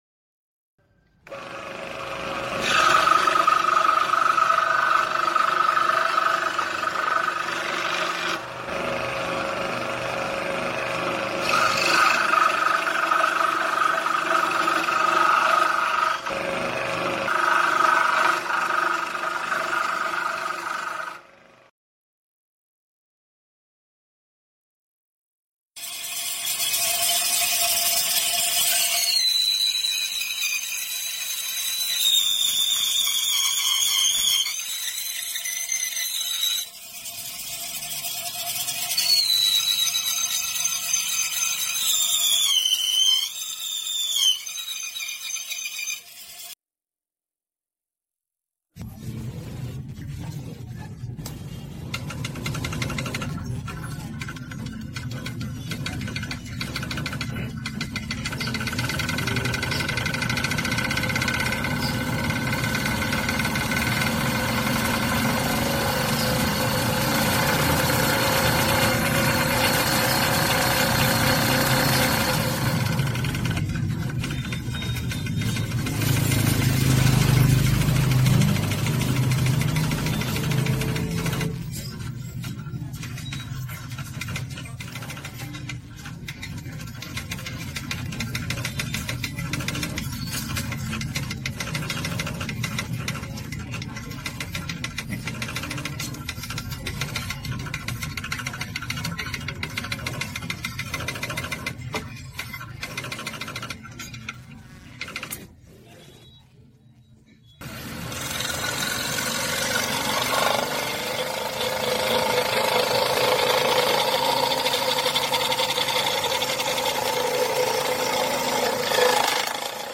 Tổng hợp tiếng máy Cưa Lọng cắt gỗ, thợ mộc, xưởng gỗ…
Thể loại: Tiếng động
Description: Tổng hợp tiếng cưa lọng, tiếng cưa gỗ, tiếng máy cắt, tiếng cưa điện, âm thanh xưởng mộc... hiệu ứng âm thanh hoạt động cắt gỗ trong nghề mộc. Những tiếng rít, xoèn xoẹt vang lên từ lưỡi cưa ma sát mạnh với gỗ, tạo cảm giác sinh động như đang ở trong xưởng.
tong-hop-tieng-may-cua-long-cat-go-tho-moc-xuong-go-www_tiengdong_com.mp3